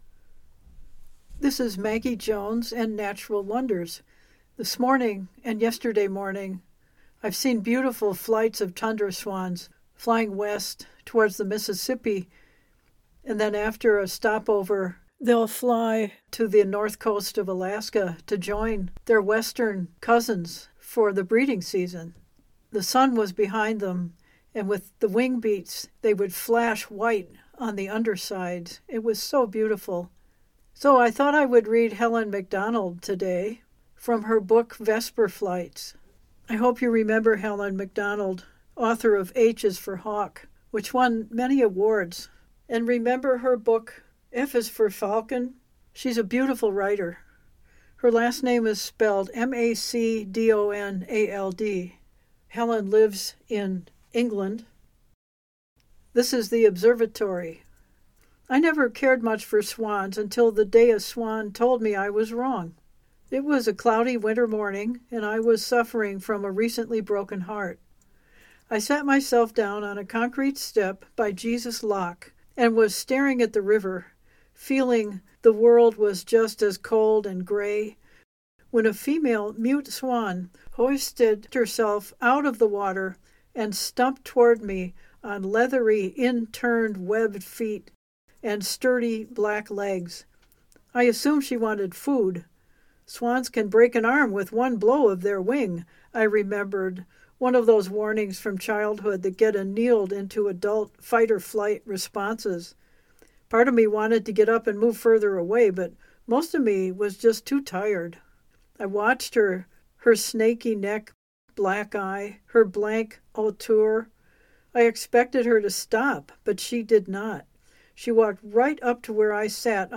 Helen Macdonald’s book of essays, Vesper Flights is full of riches. We will hear 2. The first is called The Observatory; Helen goes to the Welney Wildfowl and Wetlands Trust, to visit the swans that migrate there.